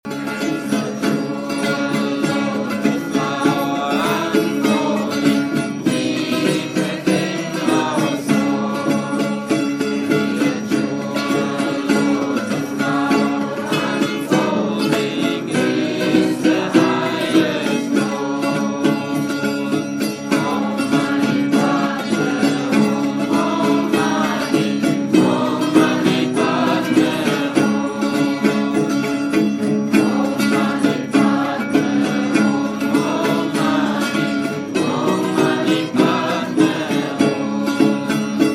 Cd Spirituelles Singen
Traditionell Buddhistisches Mantra